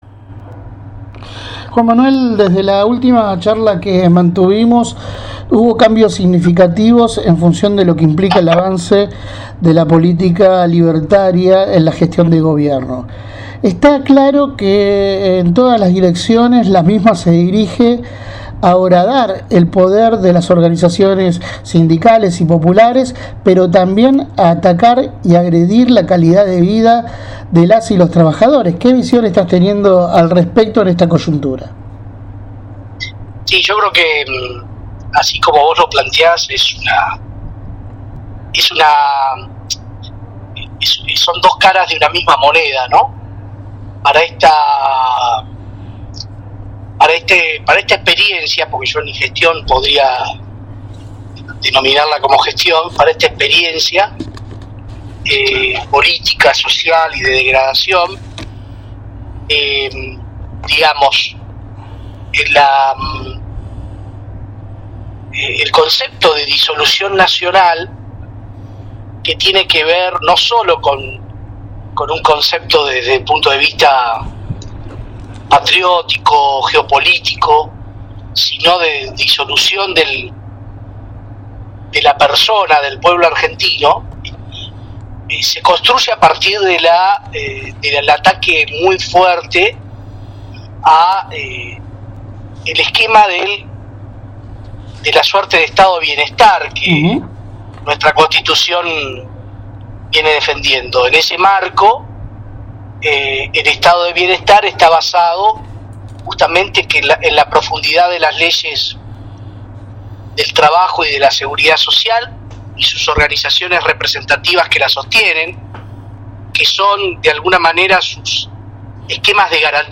A continuación reproducimos la entrevista completa: